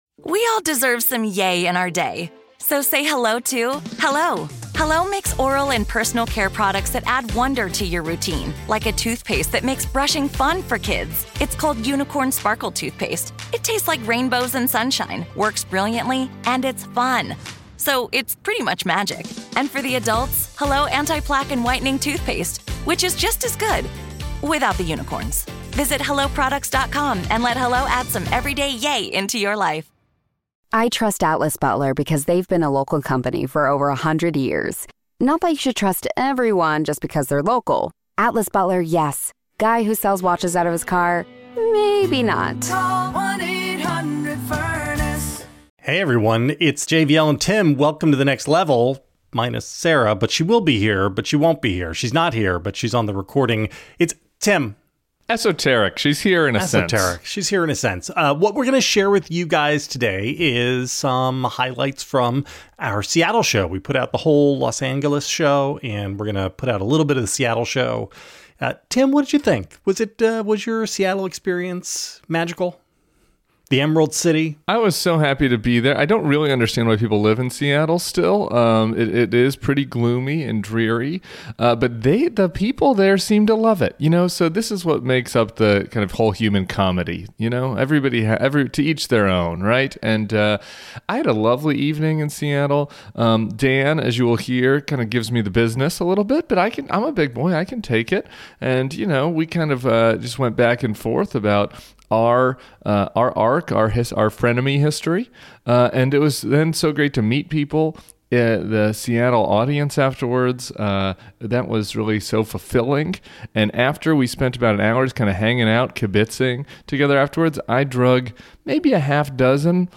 The gang finish their road trip with a live show in Seattle this weekend with columnist Dan Savage!